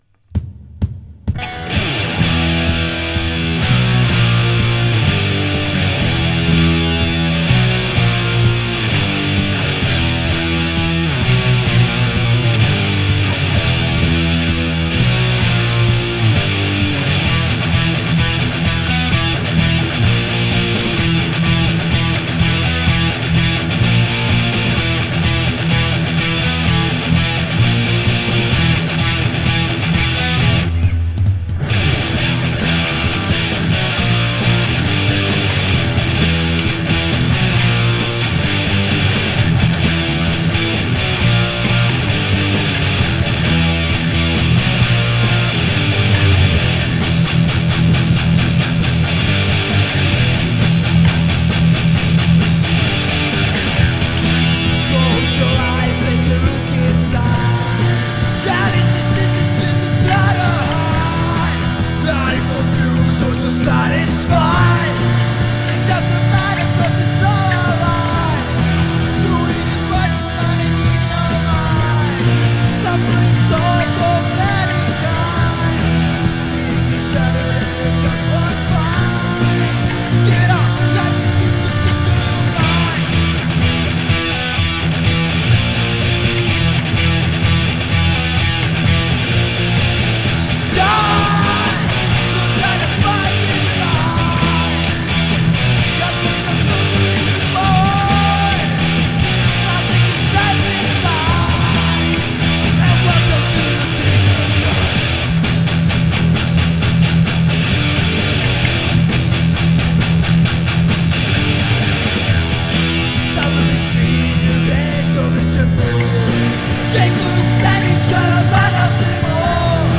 Žánr: Metal/HC